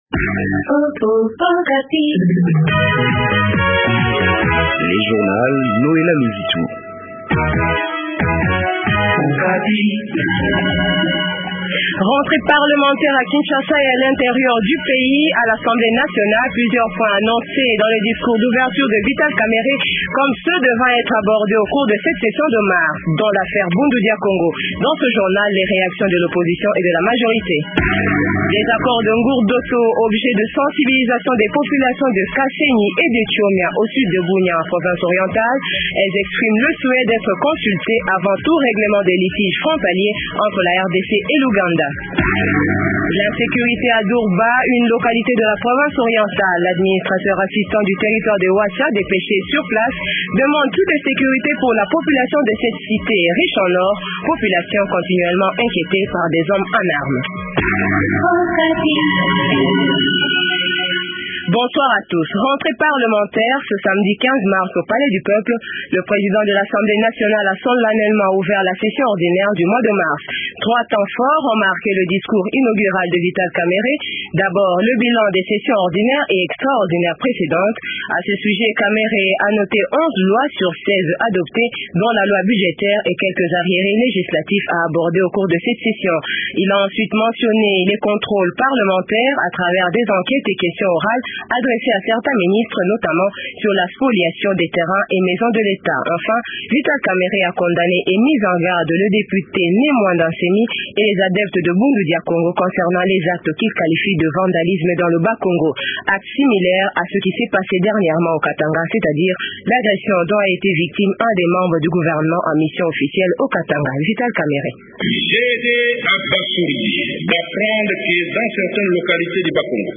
Journal Francais Soir 18h00